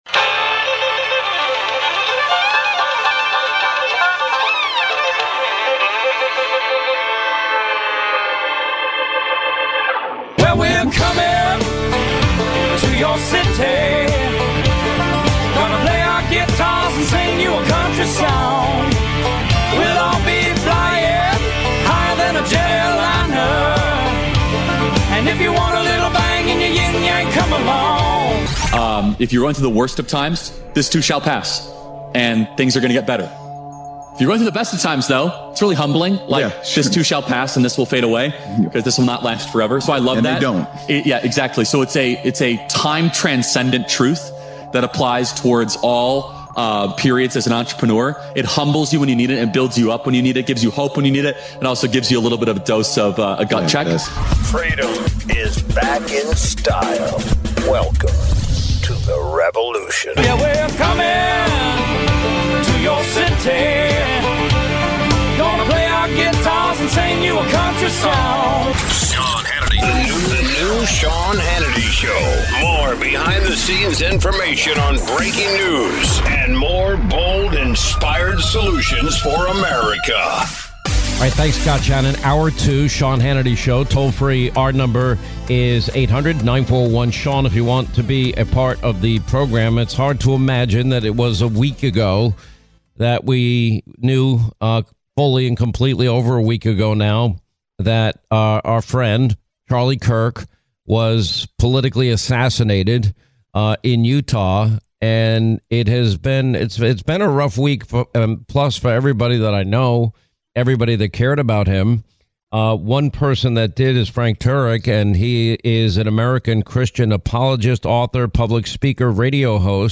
Sean Hannity radio interview (09/19/25)